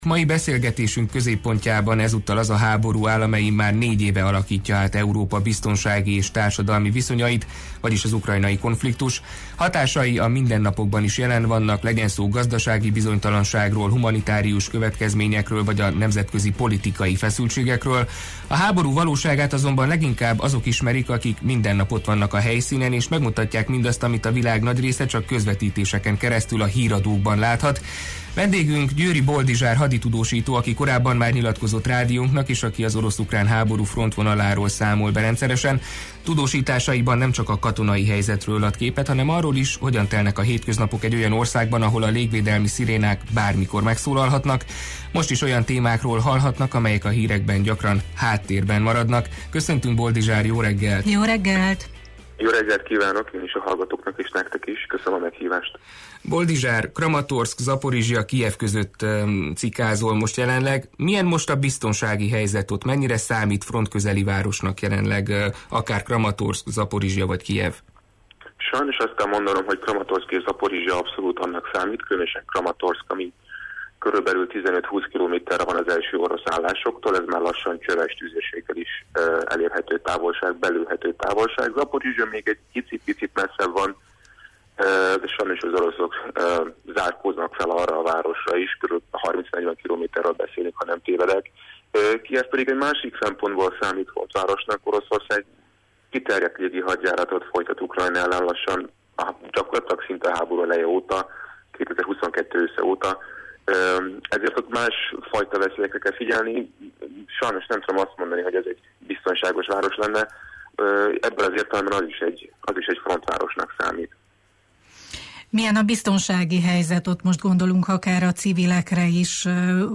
Mai beszélgetésünk középpontjában ezúttal az a háború áll, amely immár négy éve alakítja át Európa biztonsági és társadalmi viszonyait: vagyis az ukrajnai konfliktus. Hatásai a mindennapokban is jelen vannak, legyen szó gazdasági bizonytalanságról, humanitárius következményekről vagy a nemzetközi politikai feszültségekről.